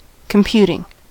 computing: Wikimedia Commons US English Pronunciations
En-us-computing.WAV